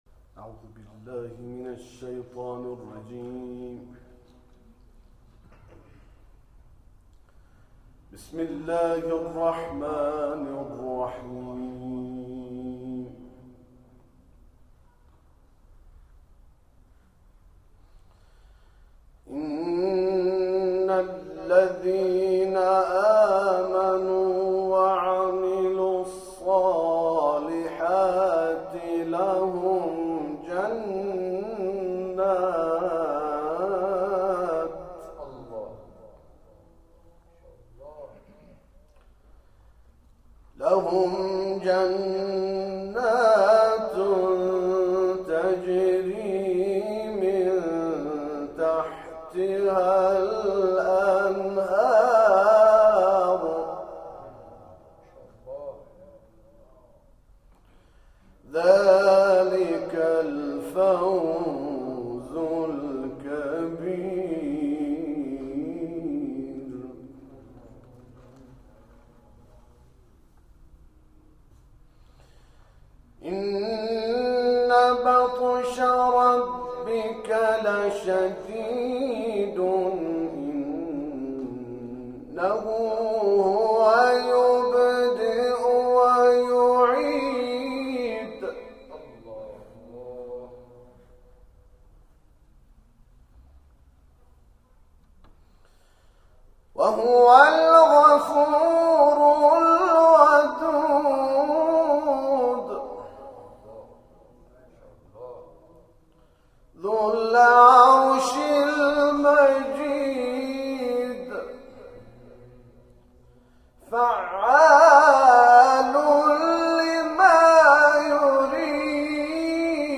جلسه قرآن در مسجدالاقصی + صوت و عکس
آیاتی از سوره مبارکه بروج را تلاوت کرد و مورد تشویق حاضران در جلسه قرار گرفت
تلاوت